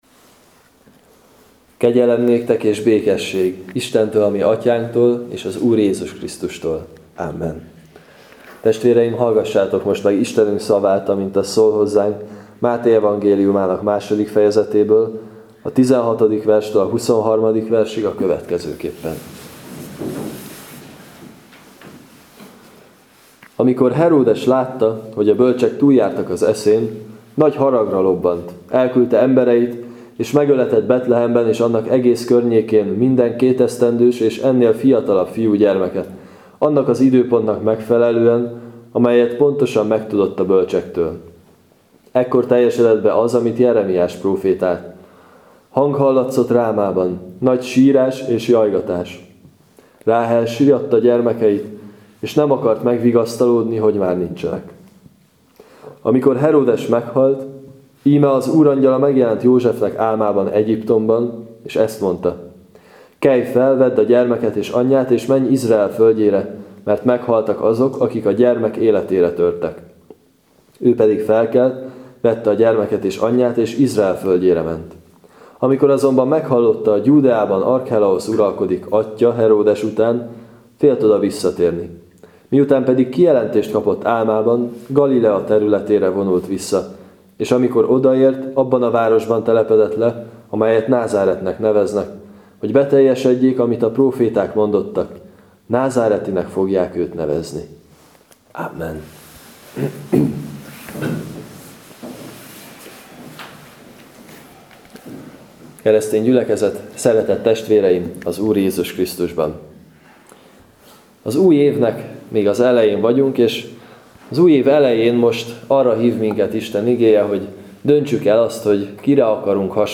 01.06. Mt 2,16-23 igehirdetés.mp3